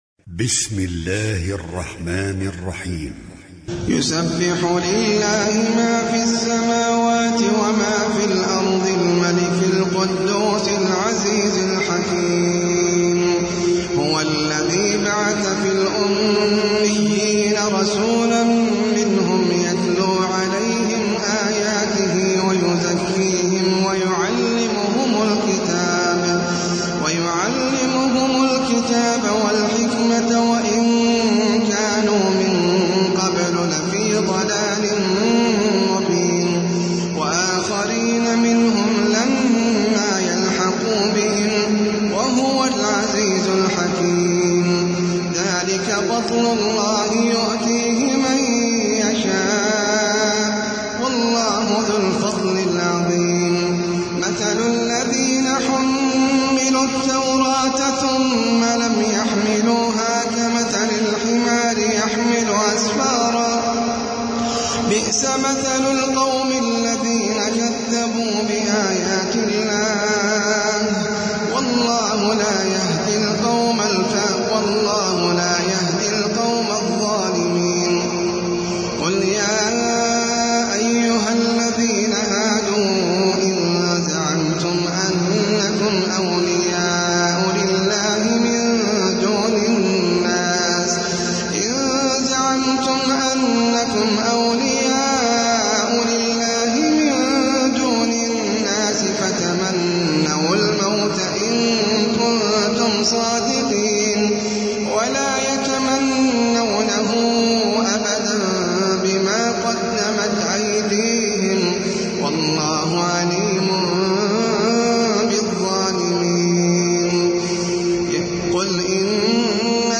سورة الجمعة - المصحف المرتل (برواية حفص عن عاصم)
جودة عالية